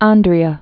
(ändrē-ə)